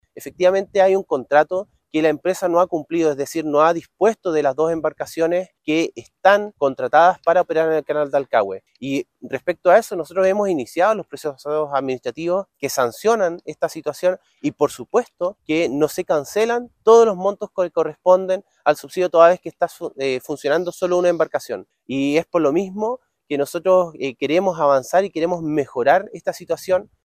Por su parte, el Seremi de Trasporte de la Región de Los Lagos, Pablo Joost, explicó que buscan entregar certezas a la comunidad y abordar los problemas de forma articulada aunque reconoció que hay un incumplimiento de contrato por parte de la naviera.